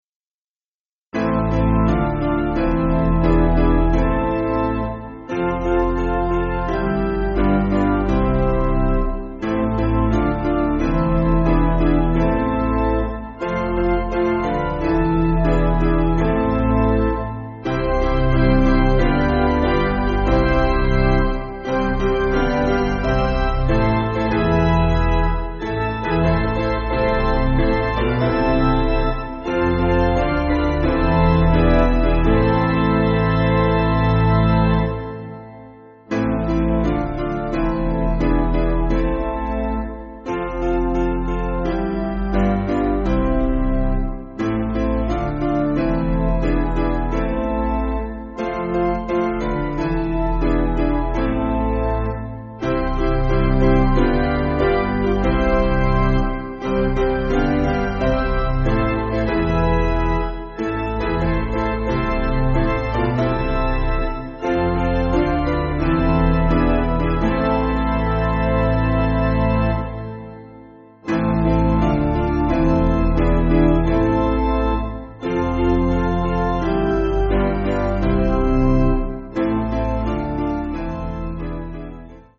Basic Piano & Organ
(CM)   5/Am